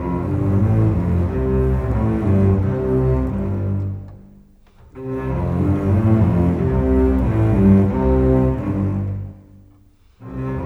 Rock-Pop 22 Bass _ Cello 01.wav